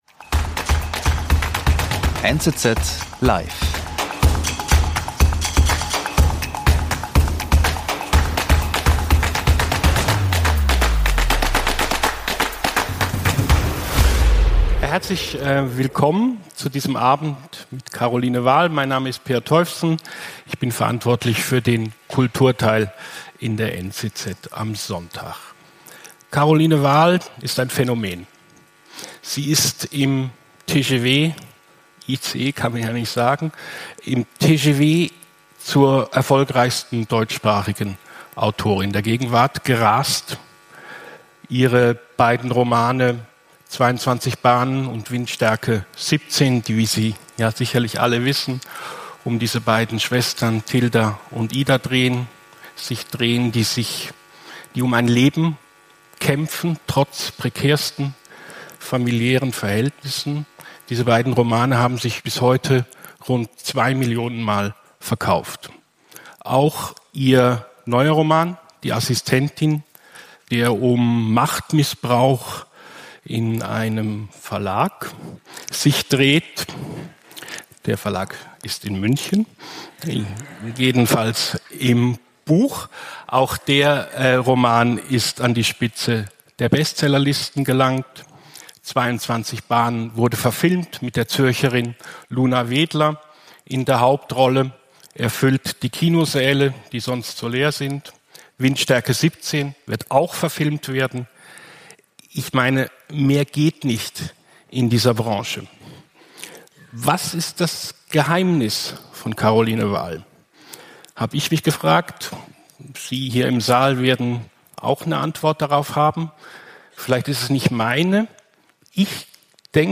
Doch es wird nicht nur geredet, Caroline Wahl liest auch aus ihrem neusten Buch.